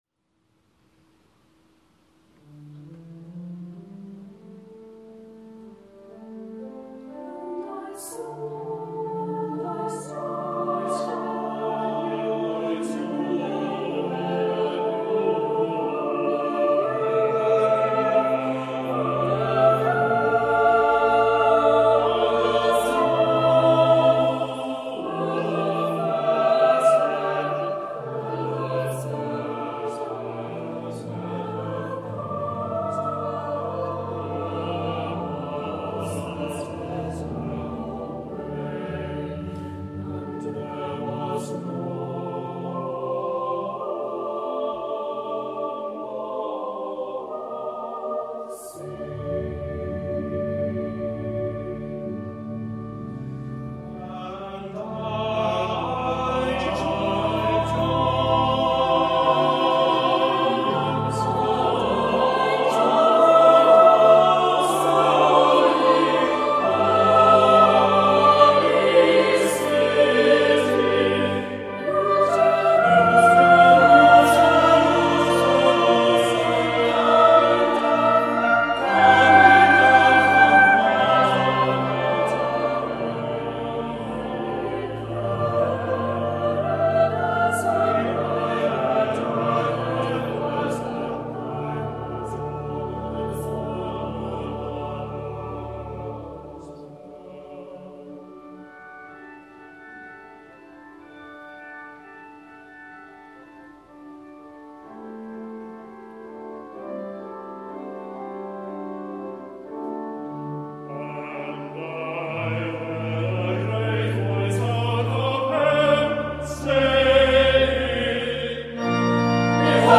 And I Saw a New Heaven, by Edgar Bainton, sung by the Priory Singers of Belfast at Truro Cathedral